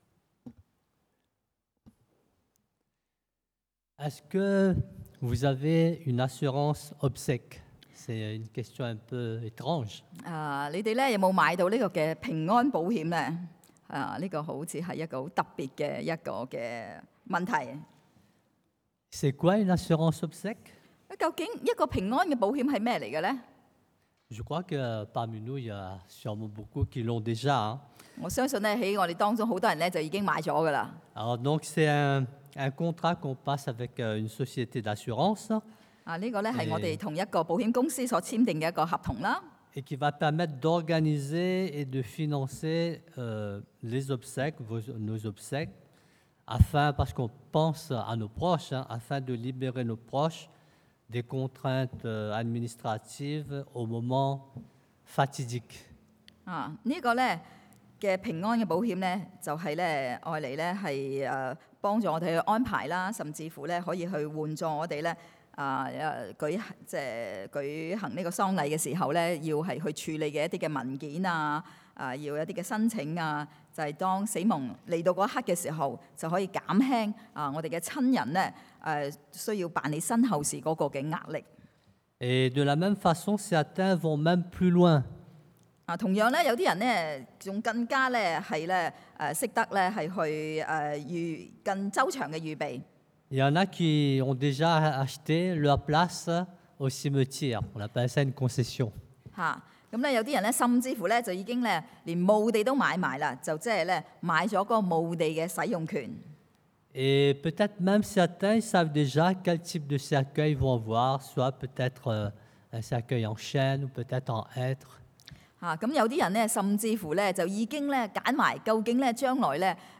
21:1-5 Type De Service: Predication du dimanche « La victoire ultime 終極的勝利 Vrais ou faux chrétiens ?